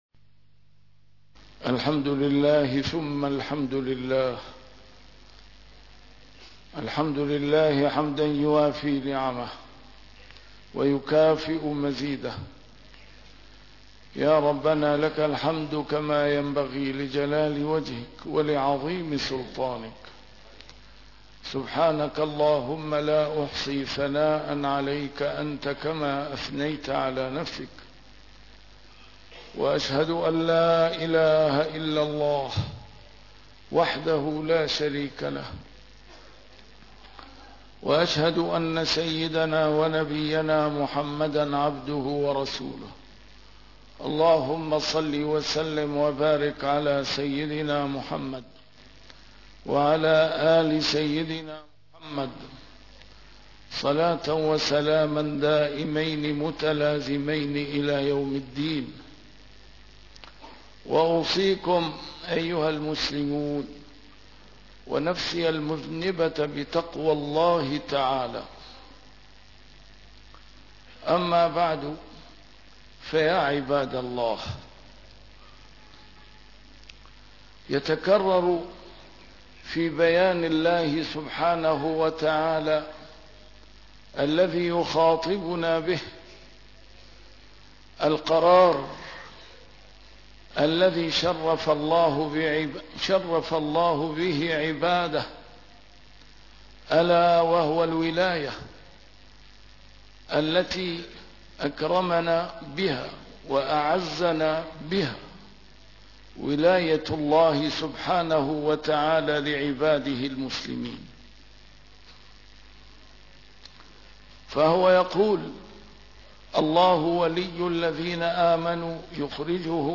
A MARTYR SCHOLAR: IMAM MUHAMMAD SAEED RAMADAN AL-BOUTI - الخطب - ولاء المؤمنين لربهم عز وجل